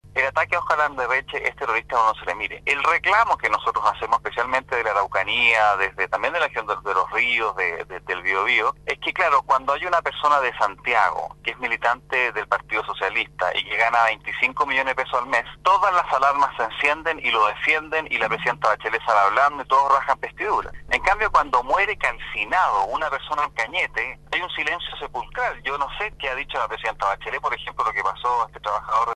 En conversación con radio Sago, el Diputado RN por La Araucanía, José Manuel Edwards, criticó la rápida acción del Gobierno en caso Landerretche, producto del atentado explosivo perpetrado el pasado viernes contra el presidente del directorio de Codelco, quien sufrió heridas leves al estallar el artefacto enviado a su casa, mientras que –según el parlamentario-  la administración de turno ha otorgado una nula respuesta frente al conflicto en La Araucanía y sobre todo por el último de ellos en que resultó una persona calcinada en el fundo Santa Clarisa de Cañete durante este fin de semana.